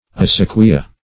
Acequia \A*ce"qui*a\ ([aum]*s[=a]"k[-e]*[.a]; Sp.
[aum]*th[=a]"k[-e]*[aum]), n. [Sp.]